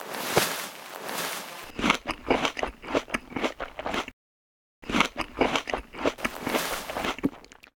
food_use.ogg